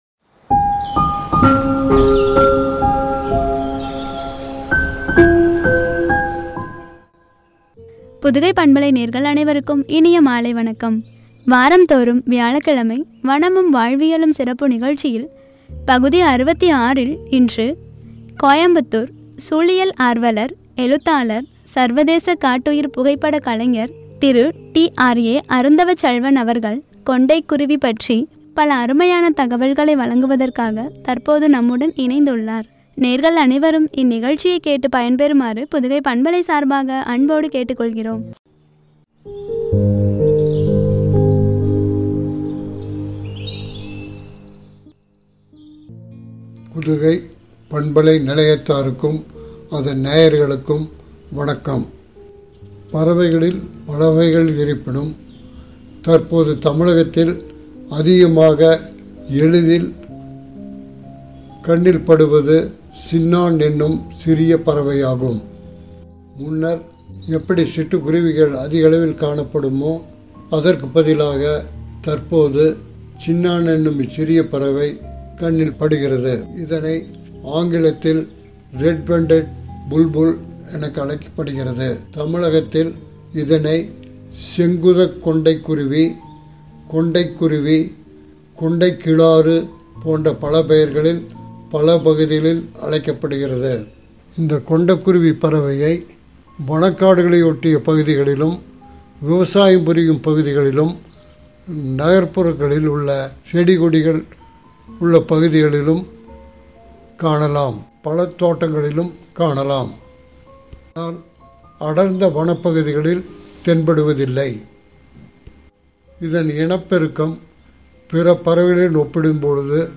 குறித்து வழங்கிய உரை.